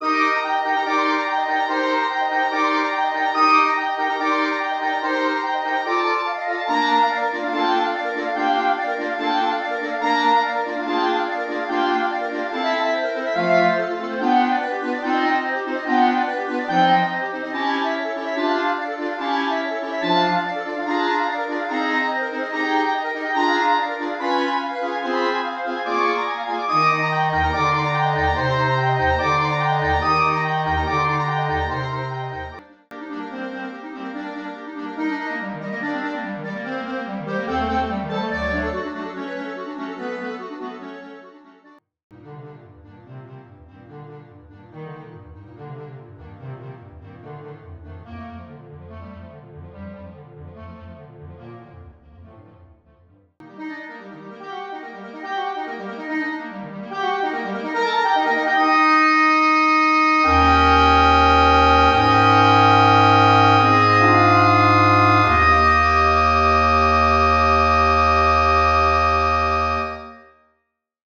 für Klarinettenquintett